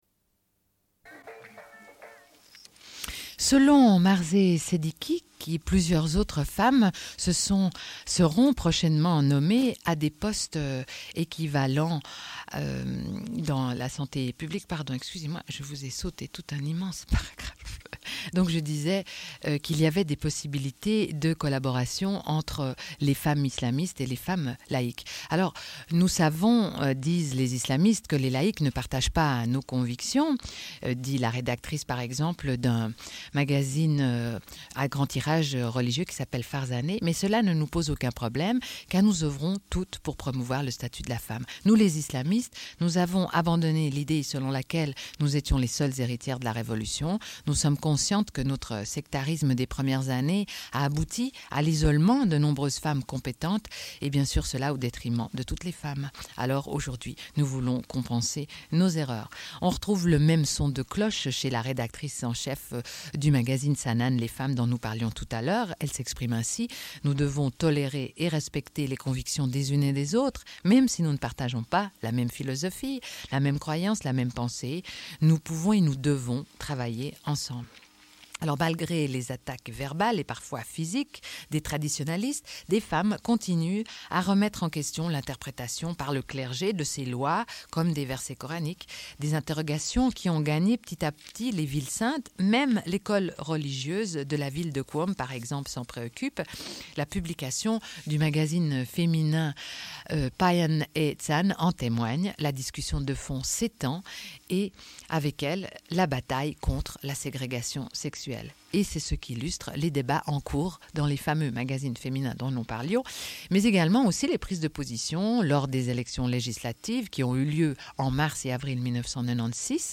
Suite de l'émission : Planète femmes, revue de presse sur les femmes à travers le monde.
Une cassette audio, face A
Radio